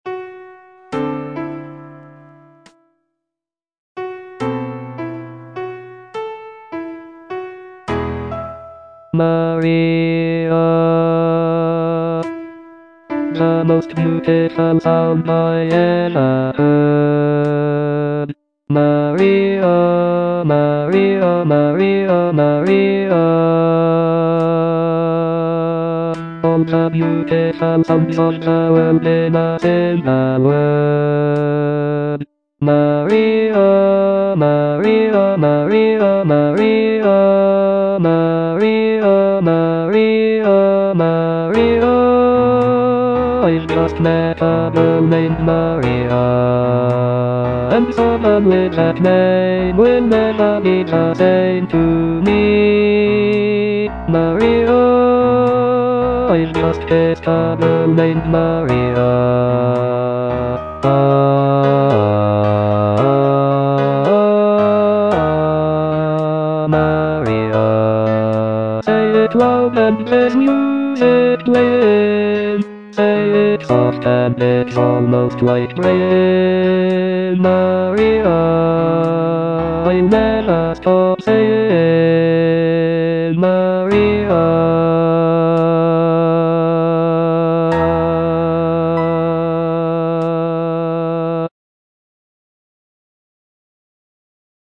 bass II) (Voice with metronome